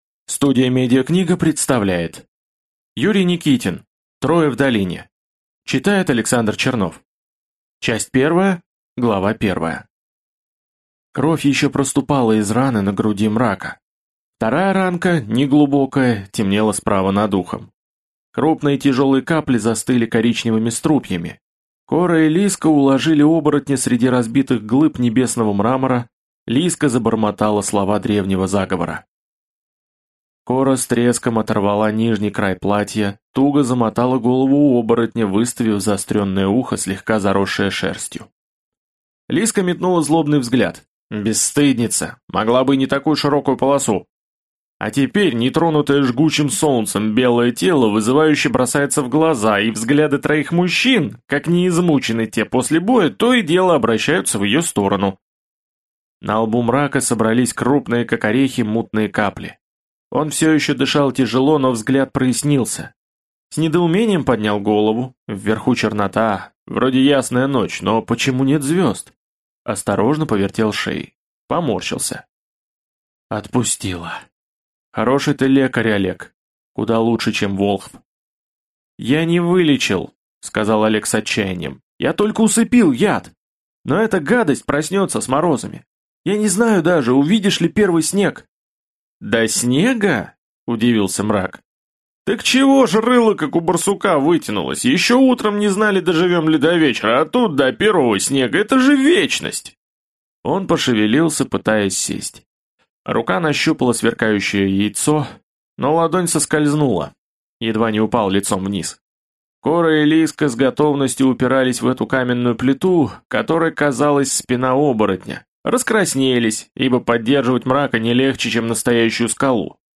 Аудиокнига Трое в Долине | Библиотека аудиокниг